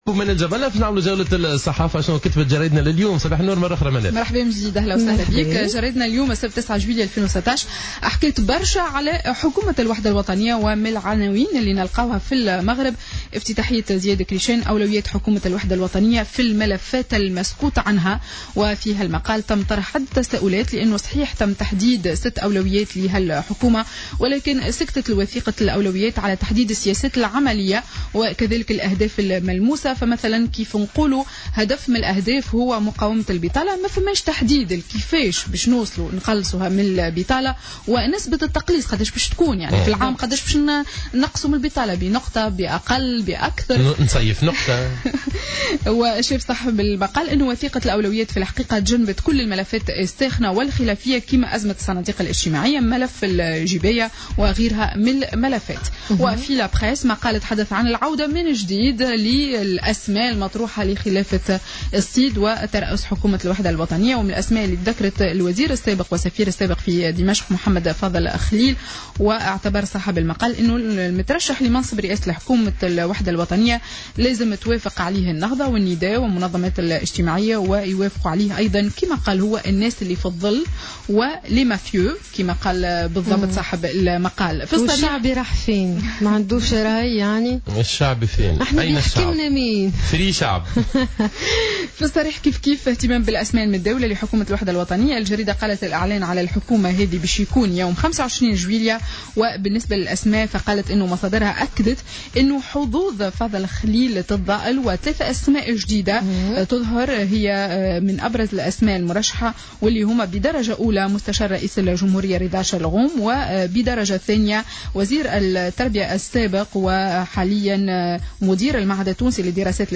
Revue de presse du samedi 9 Juillet 2016